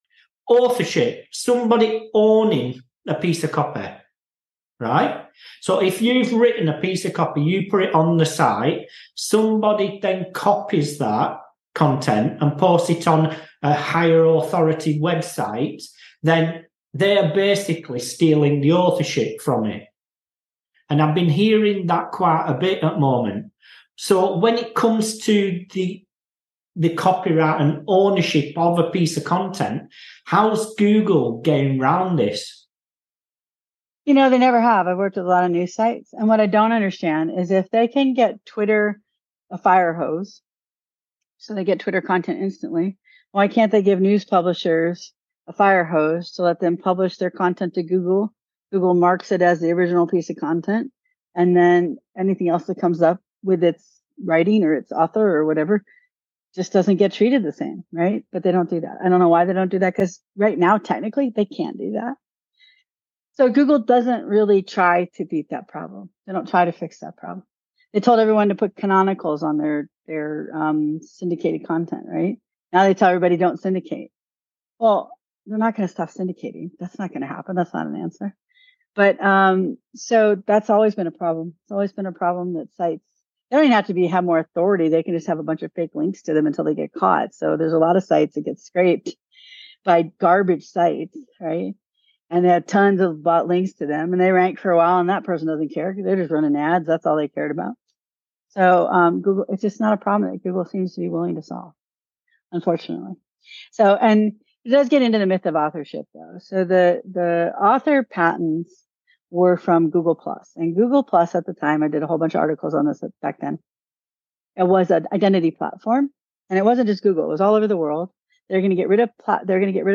The Unscripted SEO Interview Podcast